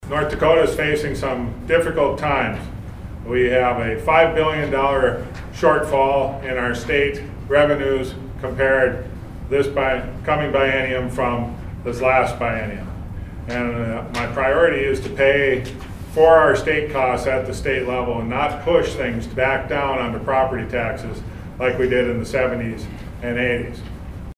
Gubernatorial Candidate Marvin Nelson was part of the press conference at the Gladstone Inn & Suites and opened with the fact that North Dakota was already facing a huge shortfall in the state budget.